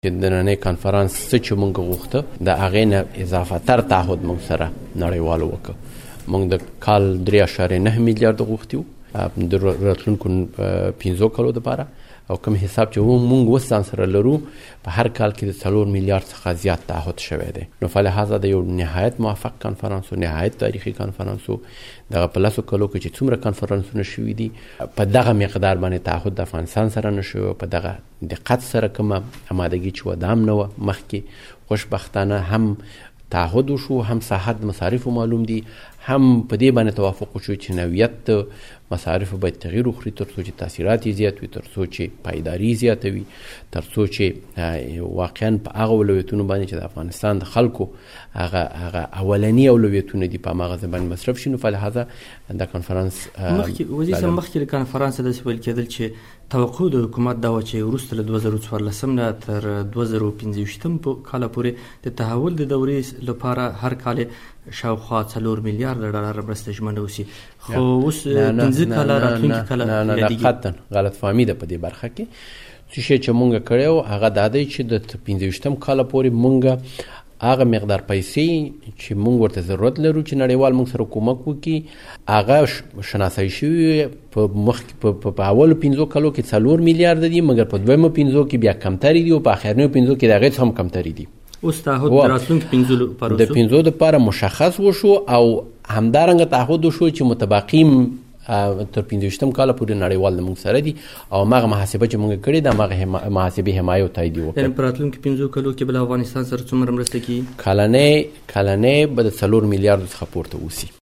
له حضرت عمر زاخېلوال سره مرکه